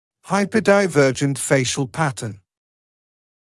[ˌhaɪpədaɪ’vɜːʤənt ‘feɪʃl ‘pætn][ˌхайрэдай’вёːджэнт ‘фэйшл ‘пэтн]гипердивергентный тип лица (длинный тип лица с большим углом нижней челюсти и увеличенной высотой нижней трети)